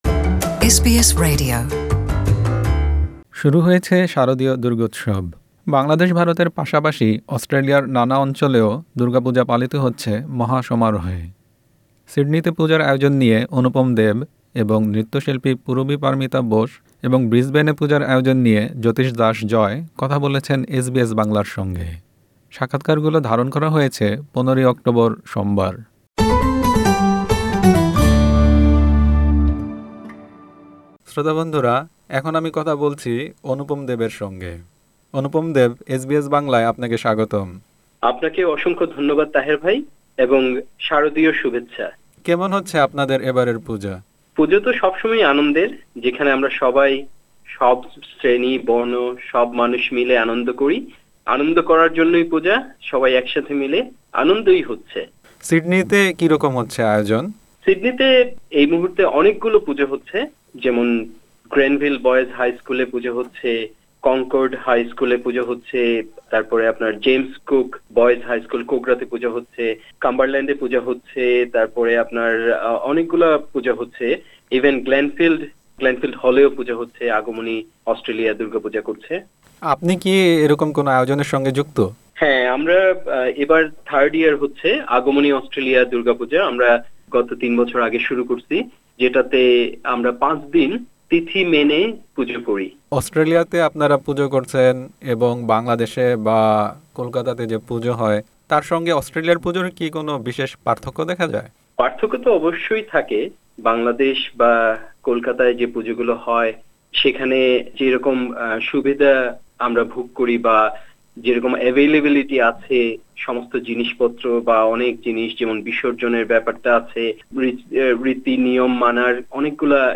সাক্ষাৎকারগুলো ধারণ করা হয়েছে ১৫ অক্টোবর সোমবার।